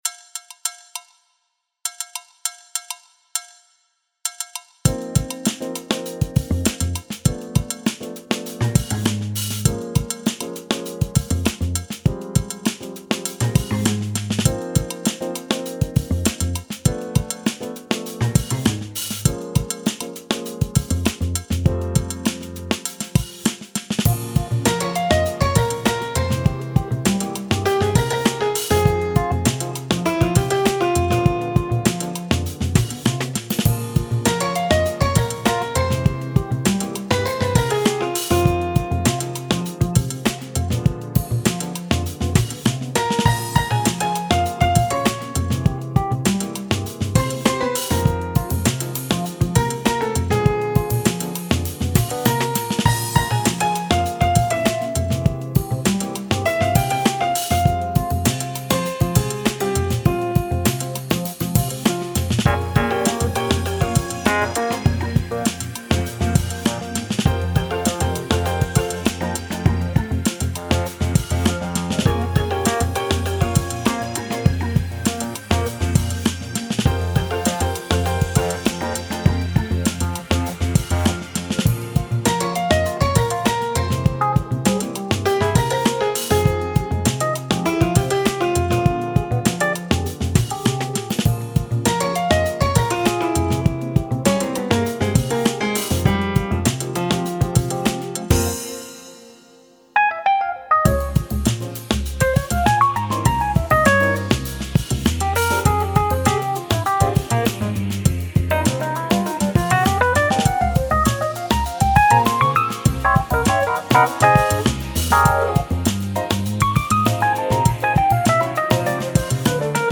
Лёгкая пьеса для компьютера без оркестра